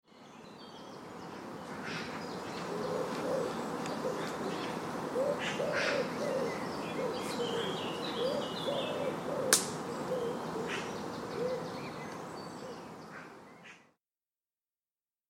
دانلود آهنگ جنگل 22 از افکت صوتی طبیعت و محیط
دانلود صدای جنگل 22 از ساعد نیوز با لینک مستقیم و کیفیت بالا
جلوه های صوتی